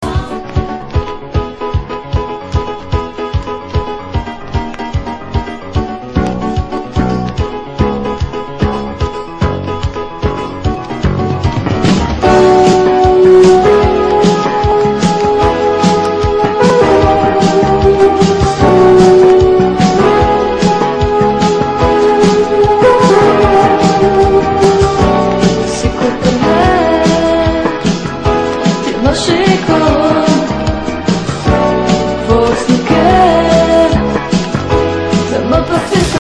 Слова слышно очень плохо, но скорее испанский (бразильцы имеют другую манеру исполнения)